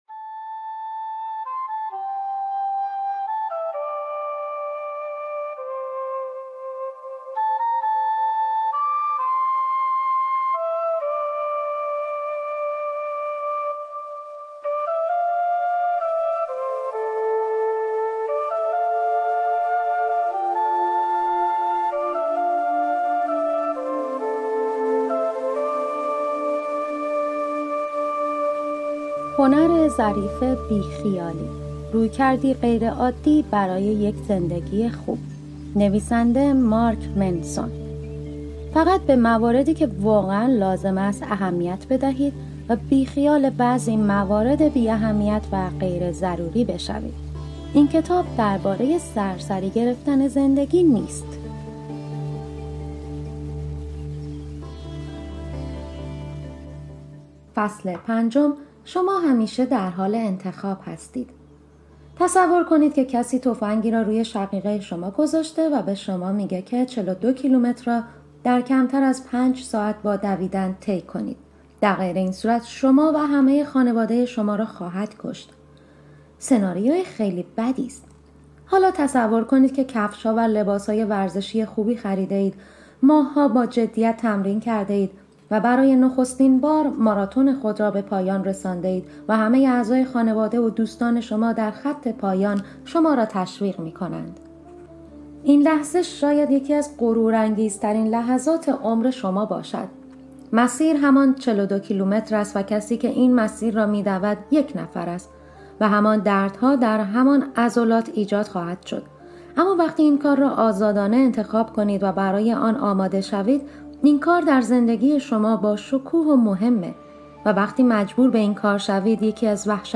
کتاب صوتی هنر ظریف بی خیالی Podcast - 05 - شما همیشه در حال انتخاب هستید | Free Listening on Podbean App